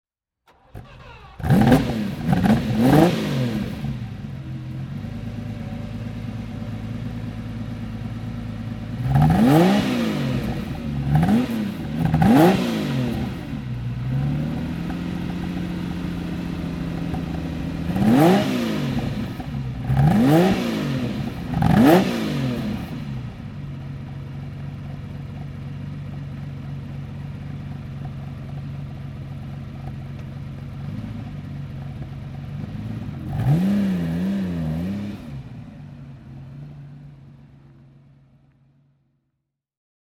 To give you an impression of the variety, we have selected ten different engine sounds.